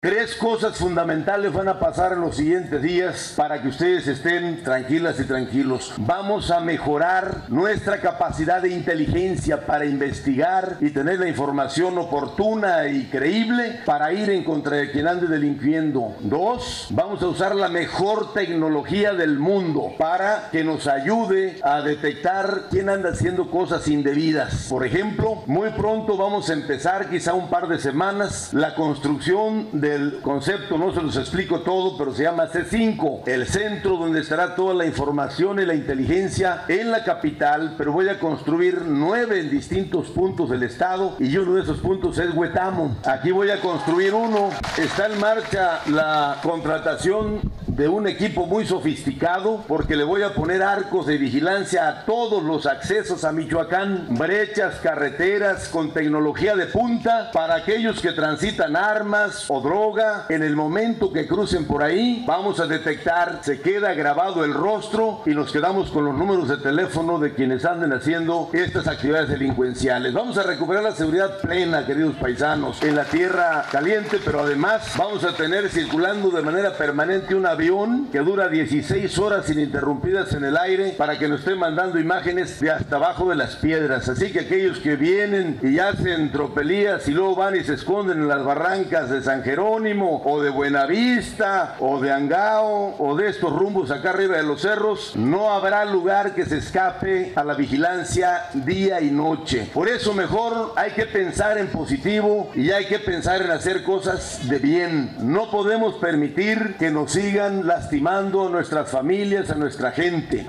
El cardenal de Morelia Alberto Suarez inda comparte con el auditorio la experiencia que vivió en el sínodo de obispos sobre la familia.